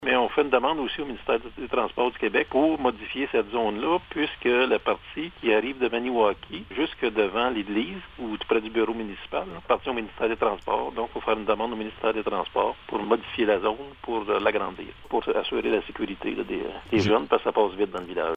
Écoutons les propos du maire de Sainte-Thérèse-de-la-Gatineau, Roch Carpentier :